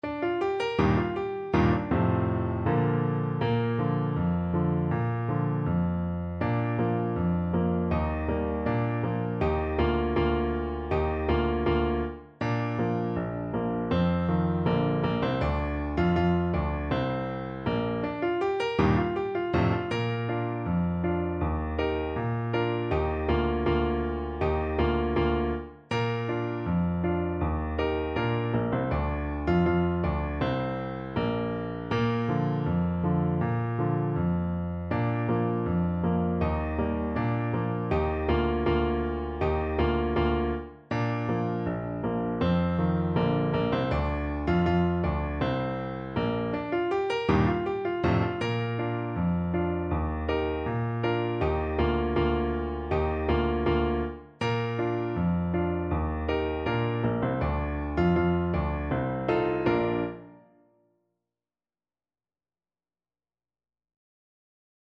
With gusto = c.80